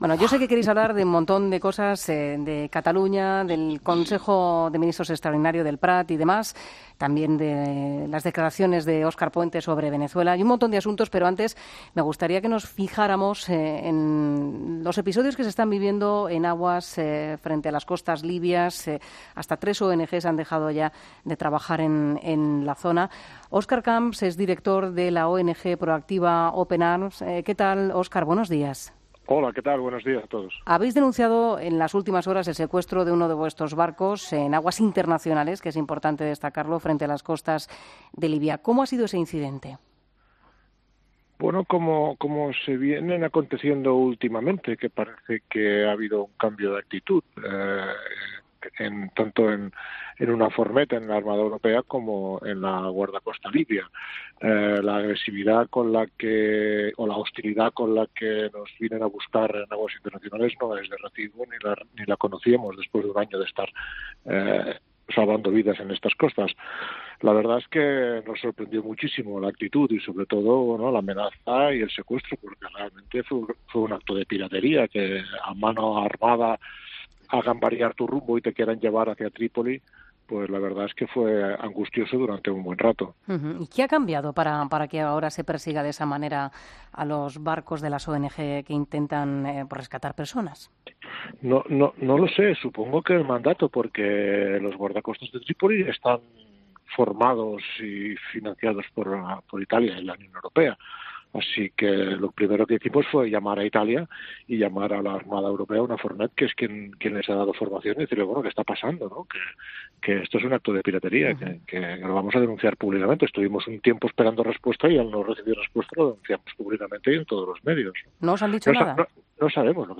Óscar Camps, fundador de la ONG ProActiva Open Arms, en 'Herrera en COPE'